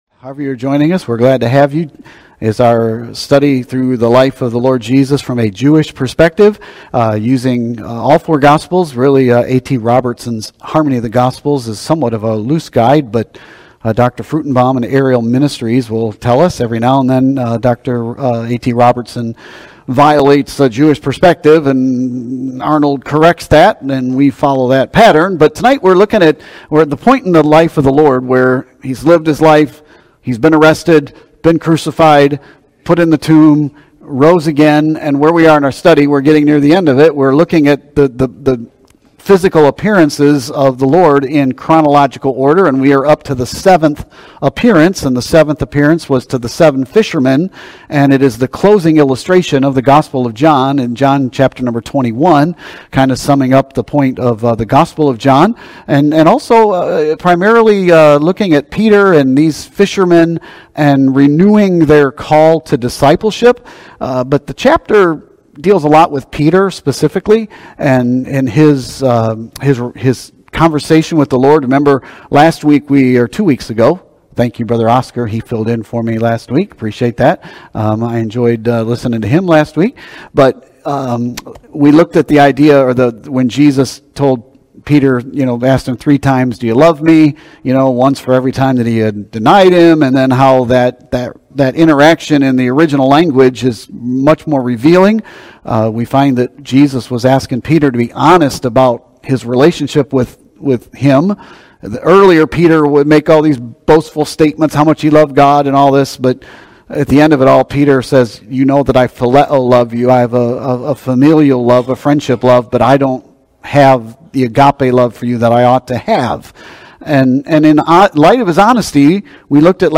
Sermons & Teachings | Open Door Baptist Church